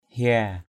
/hia:/ 1. (đg.) khóc = pleurer. hia nde gaok nde glah h`% Q^ _g<K Q^ g*H khóc sướt mướt = pleurer comme marmite et casserole (pleurer comme vache qui pisse)....